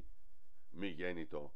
ascolta la pronunzia in greco).